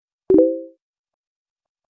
เสียง Messenger Facebook
หมวดหมู่: เสียงเรียกเข้า
am-thanh-messenger-facebook-th-www_tiengdong_com.mp3